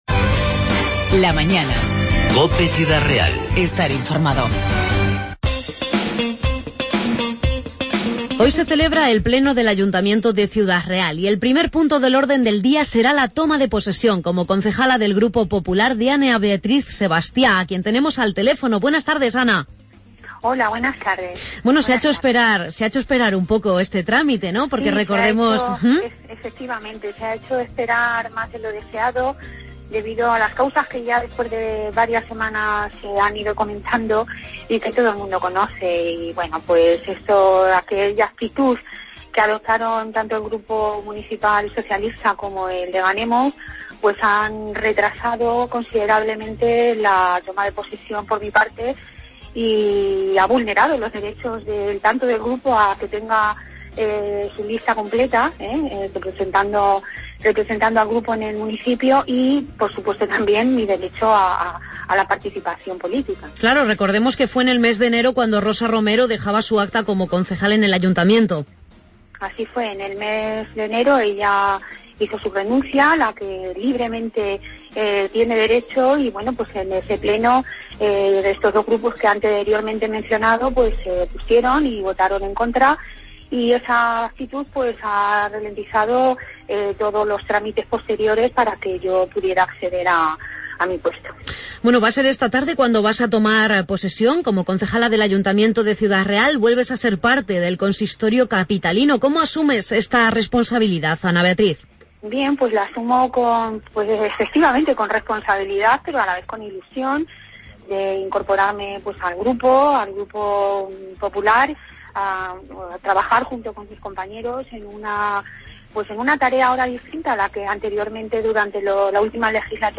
Hoy, en el Pleno del Ayuntamiento de Ciudad Real, toma posesión como concejal del grupo popular Ana Beatriz Sebastiá, concejala de educación en la pasada legislatura; y el grupo municipal de Ciudadanos presentará una moción para recovertir el patio interior del Mercado de Abastos en un mercado gastronómico. Hemos hablado en LA MAÑANA con Ana Beatriz Sebastiá y con Pedro Fernández, portavoz de Cs.